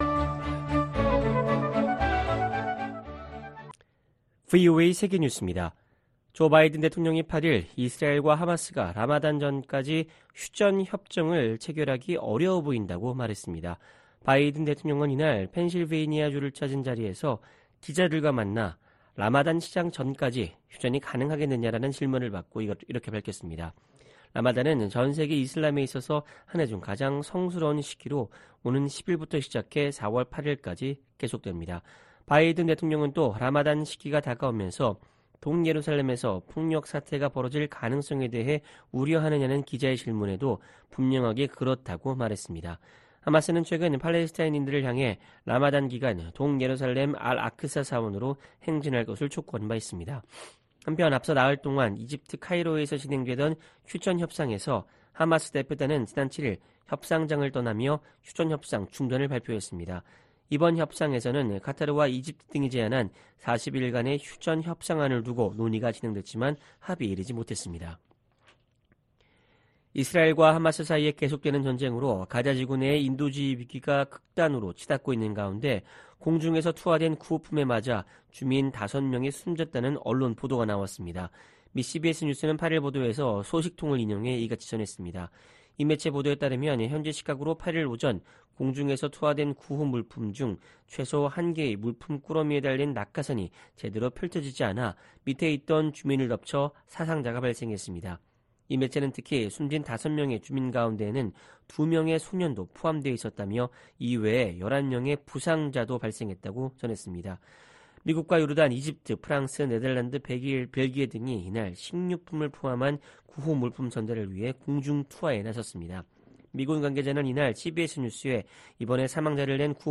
VOA 한국어 방송의 토요일 오후 프로그램 2부입니다.